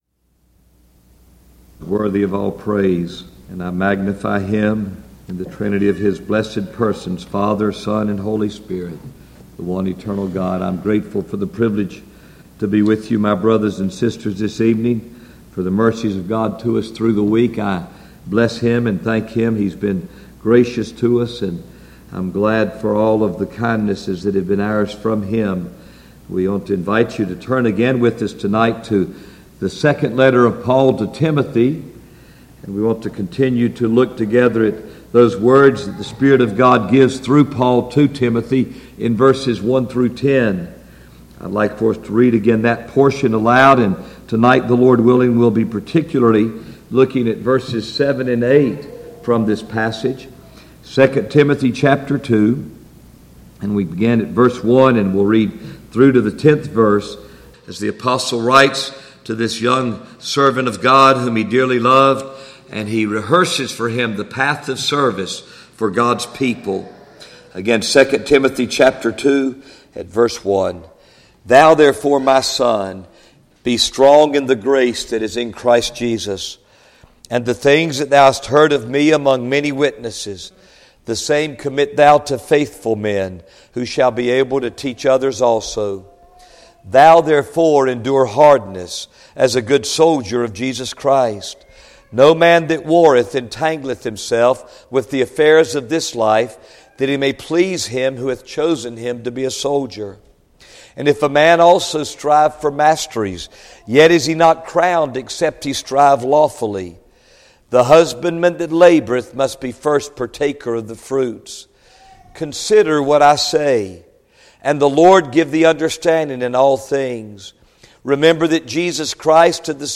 Series: 2017 July Conference Passage: 2 Timothy 2:7-8 Session: Evening Session